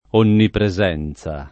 [ onnipre @$ n Z a ]